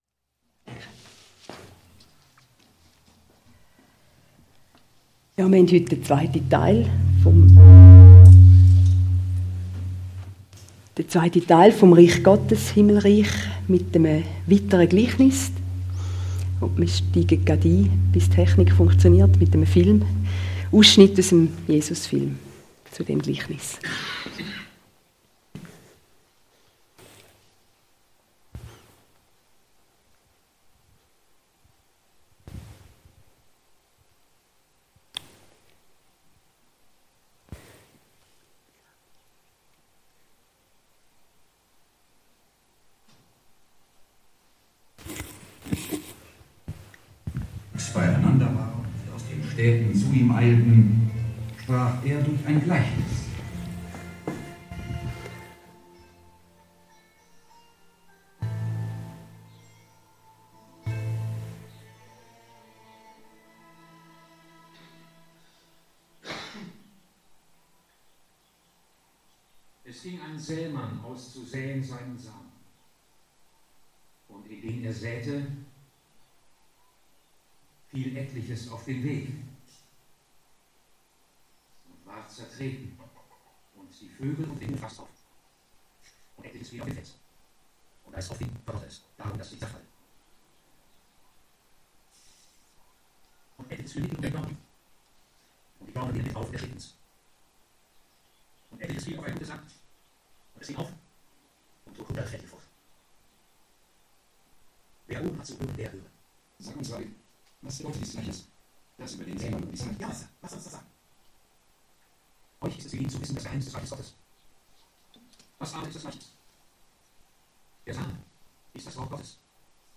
Gleichnisse Dienstart: Sonntagmorgengottesdienst « Gleichnisse 1